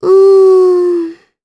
Ophelia-Vox-Deny_jp.wav